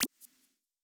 generic-hover-softer.wav